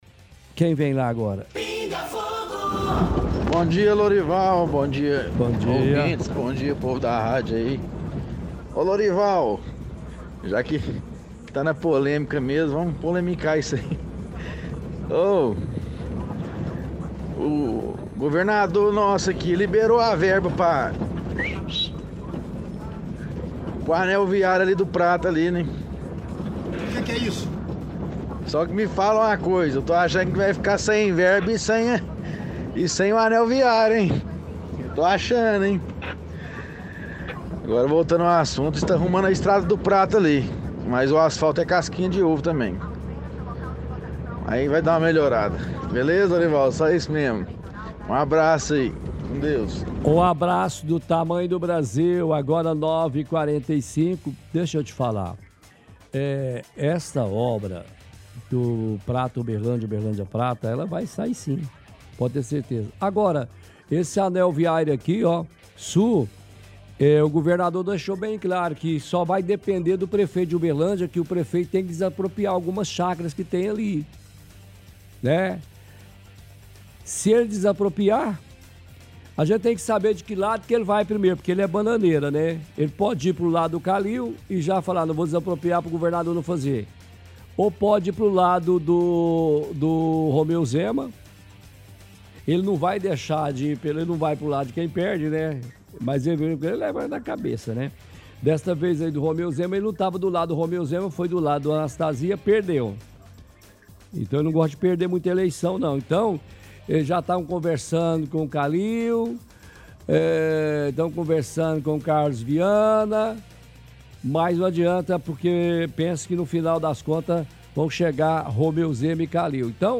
– Ouvinte fala sobre obras do anel viário sul.